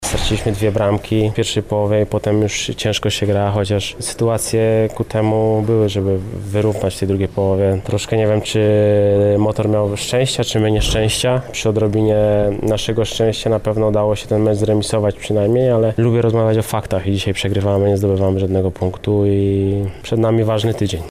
• podsumował spotkanie Mariusz Stępiński.